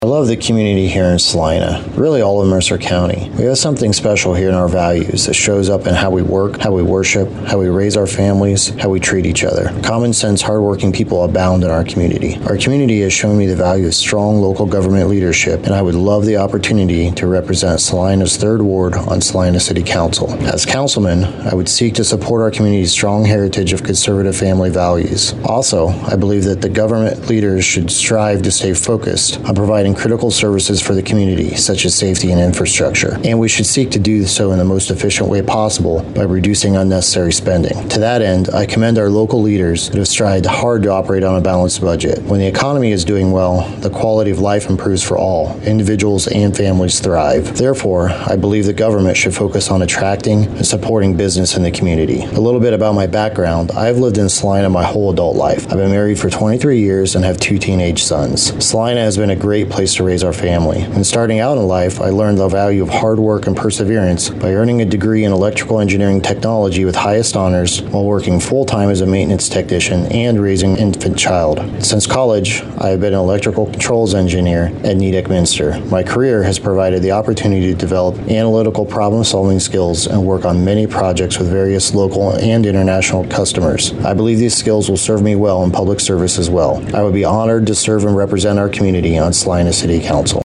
WCSM Headline News